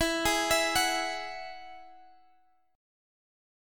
Eadd9 Chord (page 2)
Listen to Eadd9 strummed